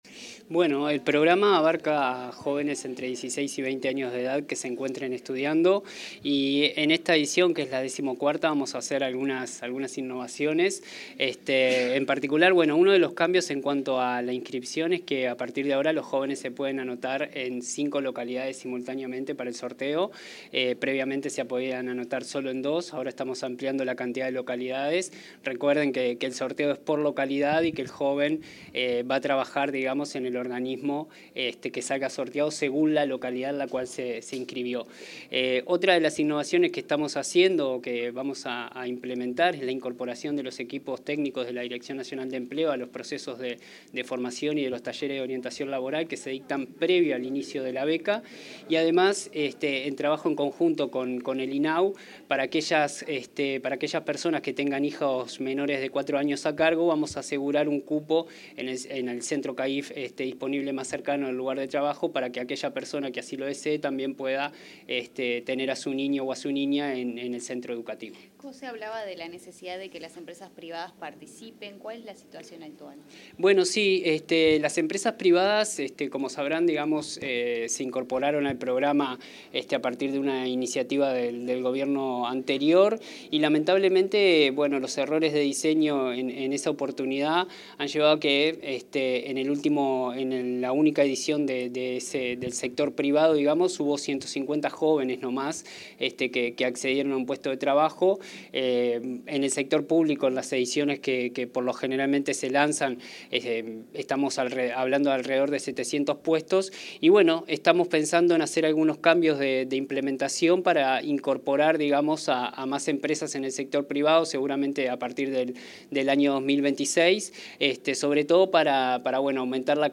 Declaraciones del director nacional de Empleo, Federico Araya
Declaraciones del director nacional de Empleo, Federico Araya 06/10/2025 Compartir Facebook X Copiar enlace WhatsApp LinkedIn Tras la presentación de la 14.ª edición del programa Yo Estudio y Trabajo, el titular de la Dirección Nacional de Empleo, Federico Araya, efectuó declaraciones a los medios informativos.